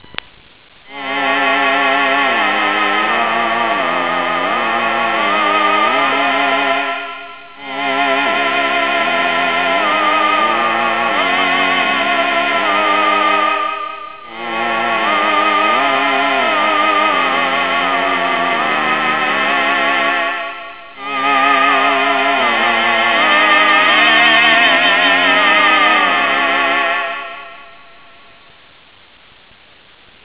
ステレオ出力なのにモノラル録音になってしまったのが残念だけども、DSPでもなく、もちろんDSP機能内蔵とも謳わないようなDIPパッケージなワンチップマイコンでも
こんな音が合成できてしまうご時世なのだな。
Voice.wav